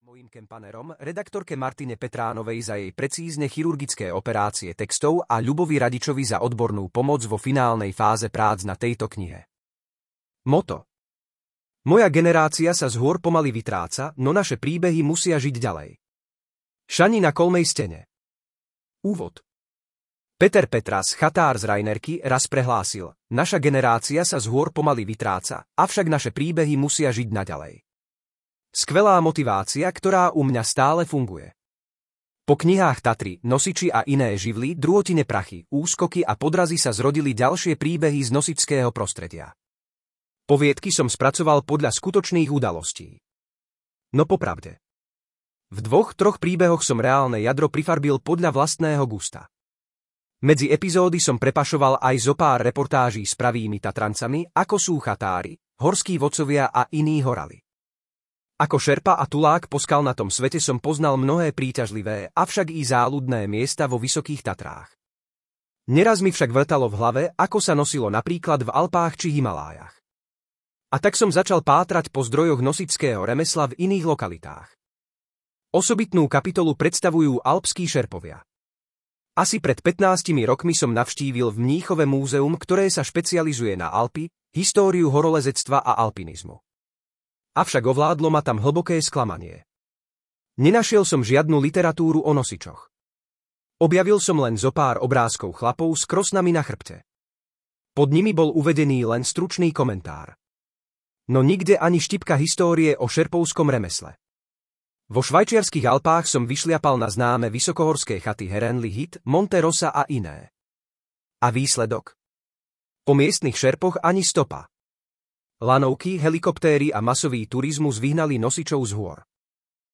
Tatranskí, alpskí, himalájski nosiči a iní horali audiokniha
Ukázka z knihy